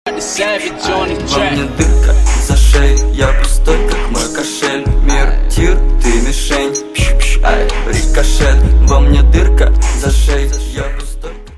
ритмичные
Хип-хоп
забавные
русский рэп
качающие